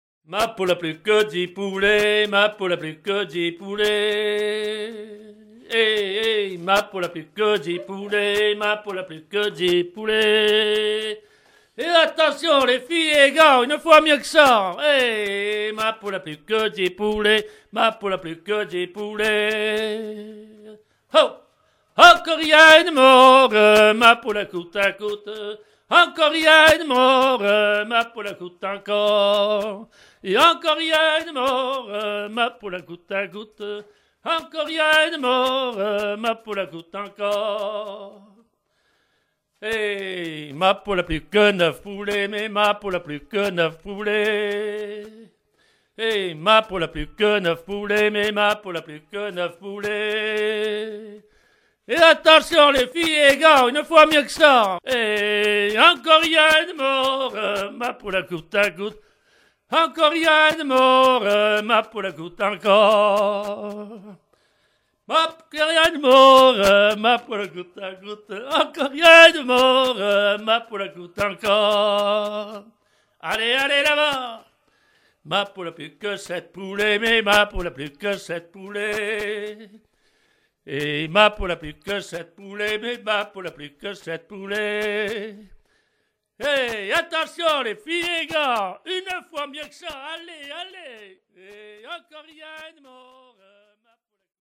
Enumératives - Nombres en décroissant
Genre énumérative
Pièce musicale éditée